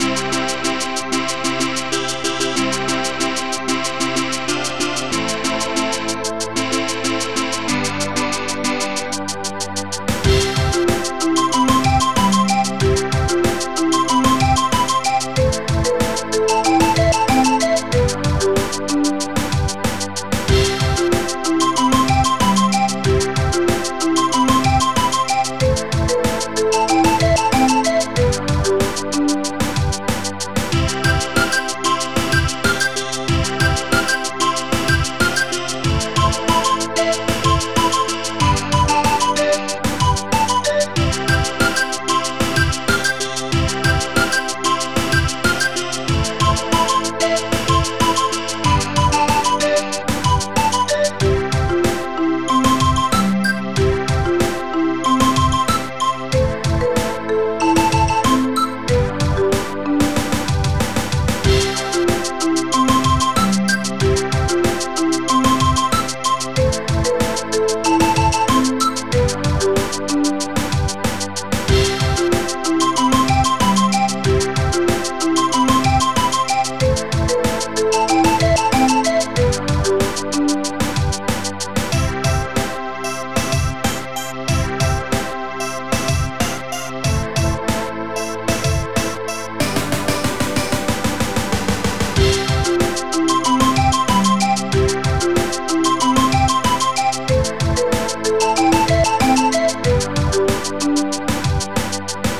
Type Amos Music Bank Tracker
ST-01:Shaker
ST-01:Marimba